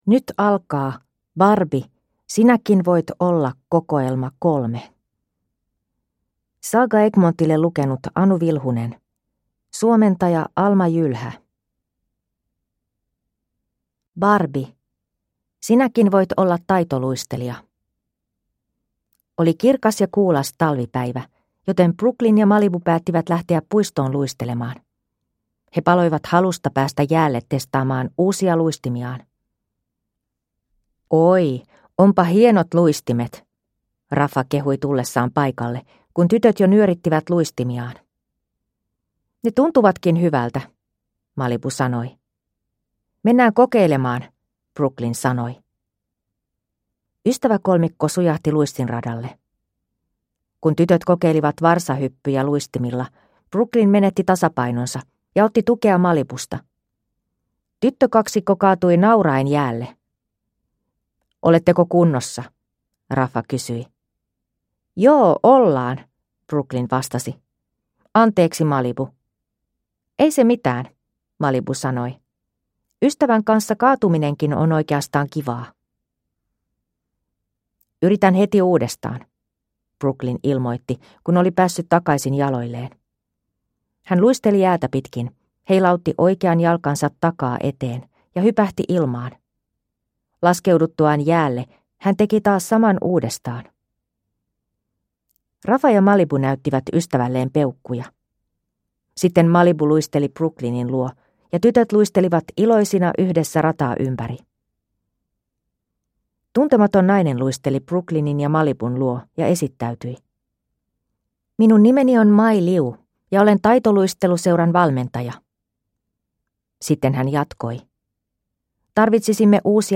Barbie – Sinäkin voit olla -kokoelma 3 (ljudbok) av Mattel